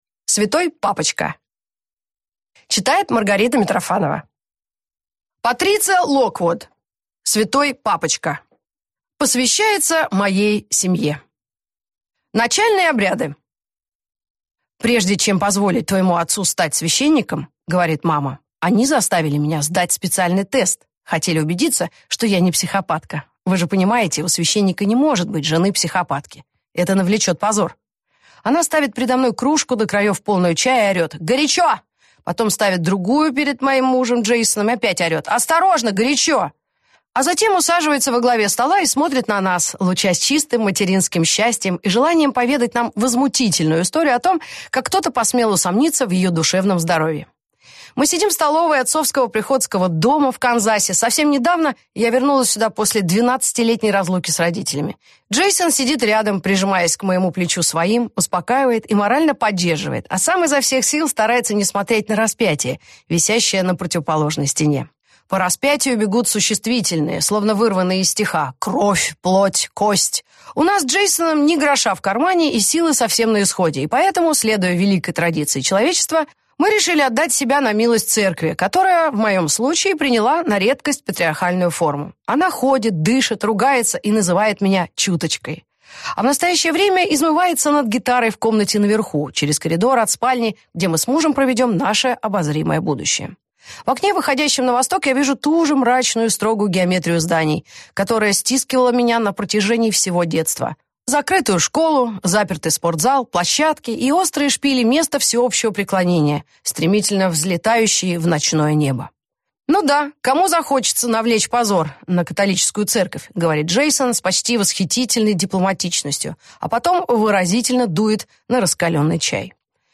Аудиокнига Святой папочка | Библиотека аудиокниг